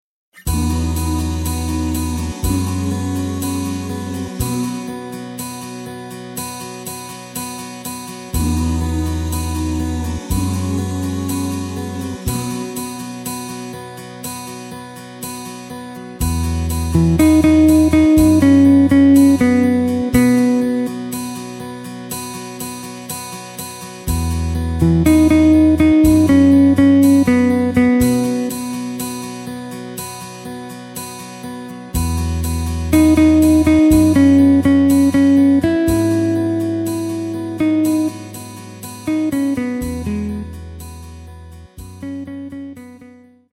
Takt:          4/4
Tempo:         122.00
Tonart:            B
POP aus dem Jahr 2012!